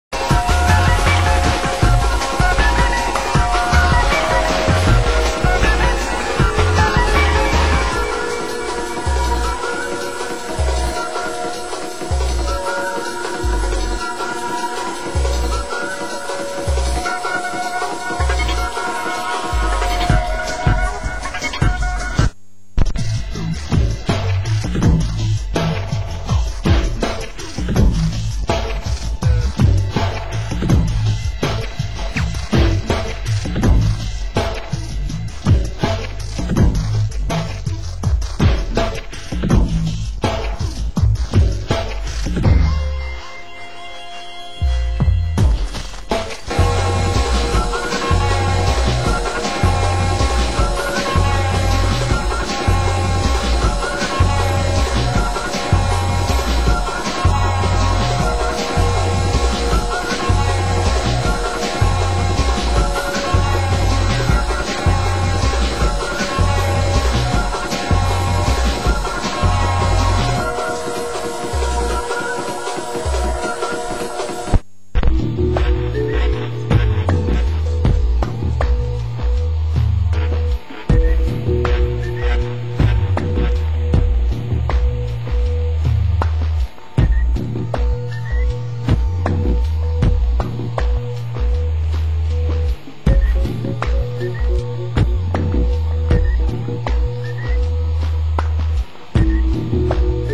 Genre: Electronica